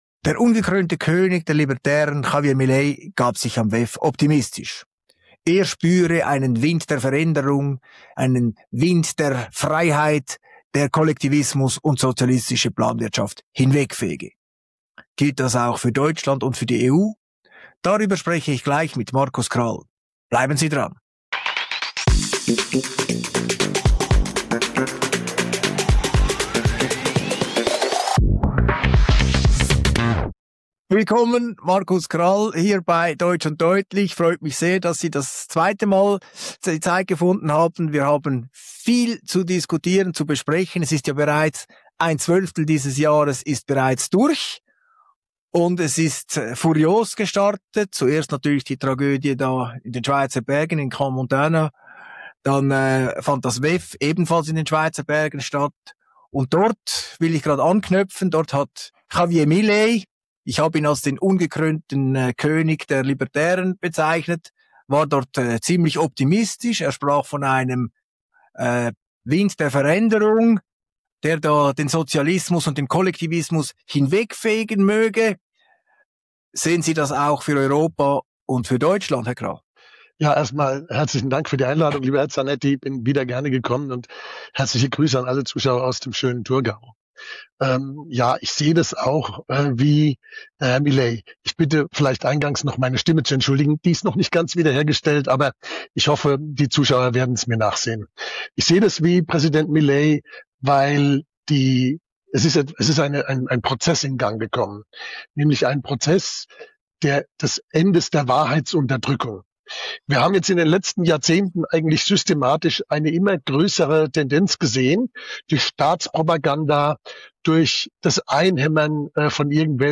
Beschreibung vor 2 Monaten Im Interview bei «deutsch und deutlich» zeigt sich Markus Krall sehr angetan von Javier Mileis Auftritt beim WEF und dessen optimistischer Botschaft eines «Windes der Veränderung», der Sozialismus und Kollektivismus hinwegfege.